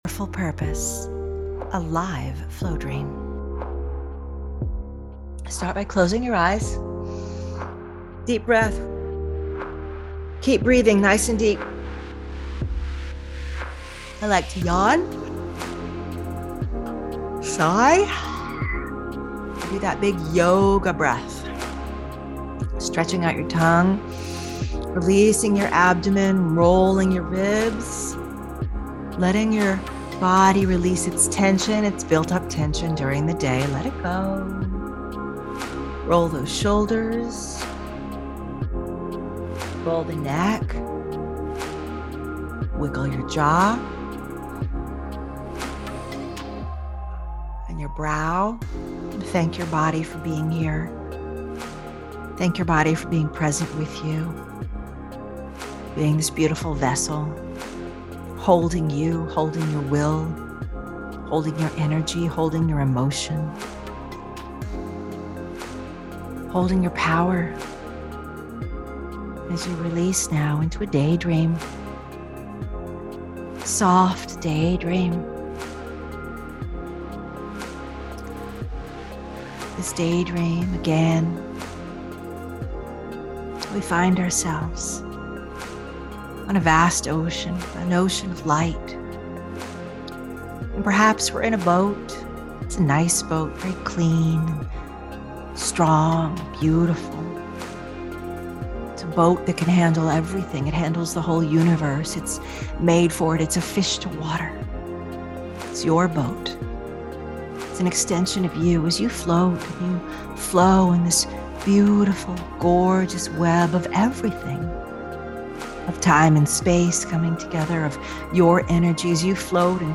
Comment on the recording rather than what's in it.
One Brilliant Thing (Live Set 2022) Experience the five BEST recordings from our live summertime "One Brilliant Thing" event. Each Flowdream focuses on a special aspect of your heart's desire.